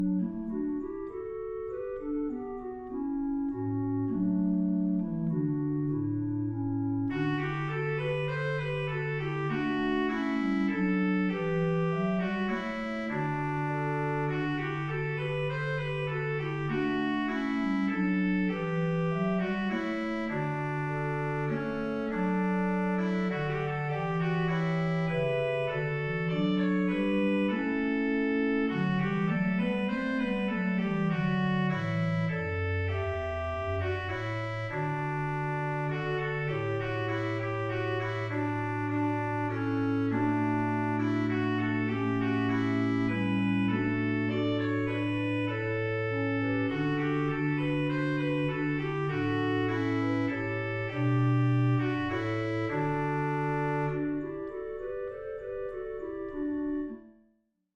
Voicing: SA Men, upper/lower, accompanied or unaccompanied
Soprano